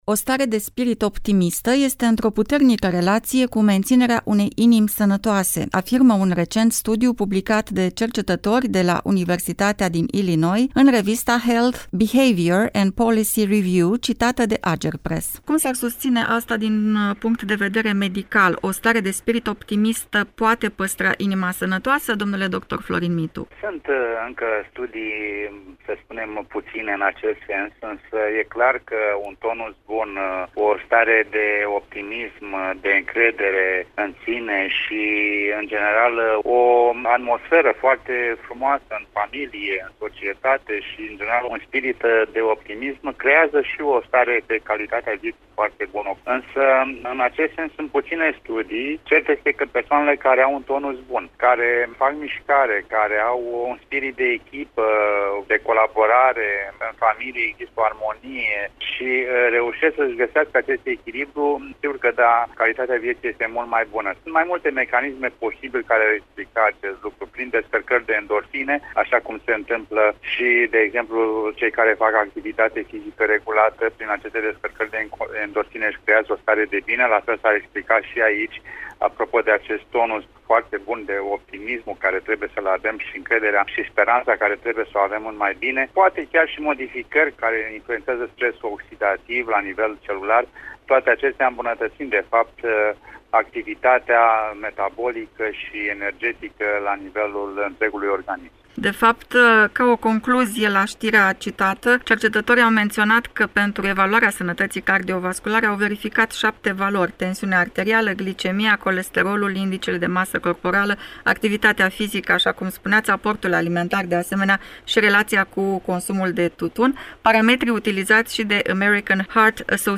Ce dovezi medicale pot veni in sprijinul acestui studiu ne spune medicul cardiolog